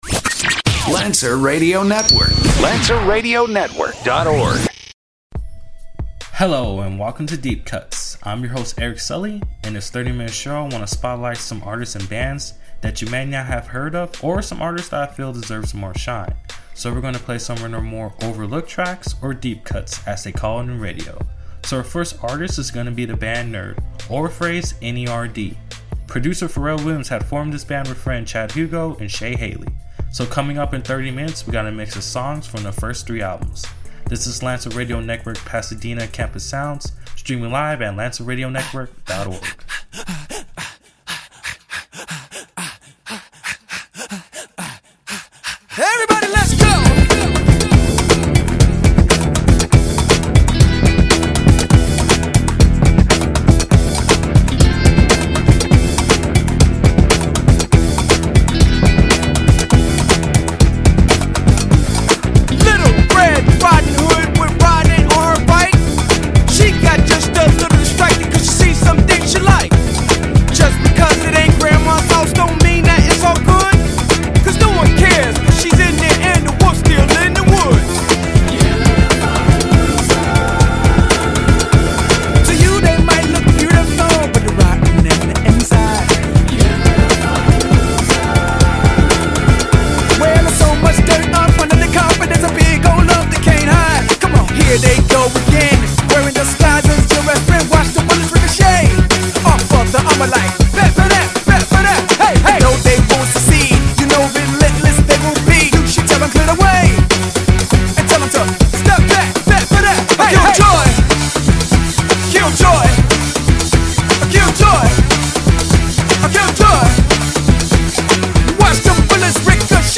So, we are gonna play some of these bands’ overlook tracks or deep cuts as they call it in radio.